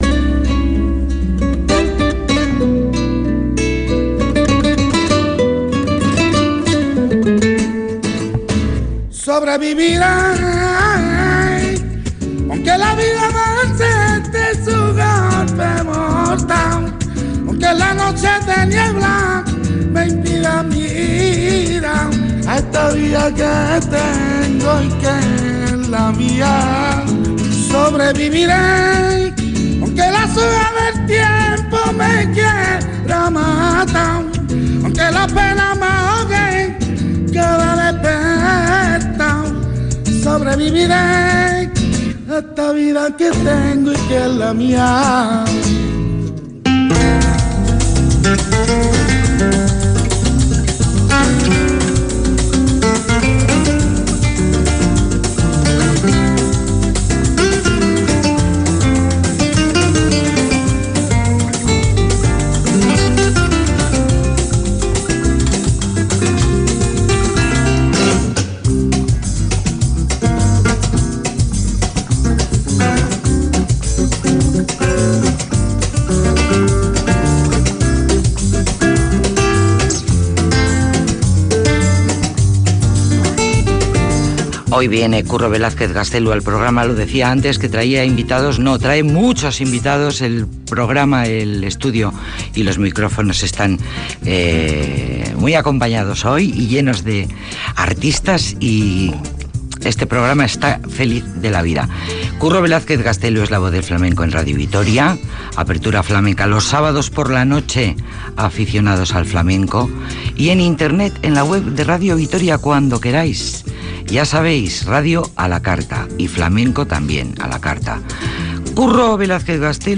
Hablamos de sus proyectos y del flamenco y nos deleitan con un catne en directo desde el estudio número uno de Radio Vitoria.